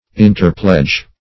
\In`ter*pledge"\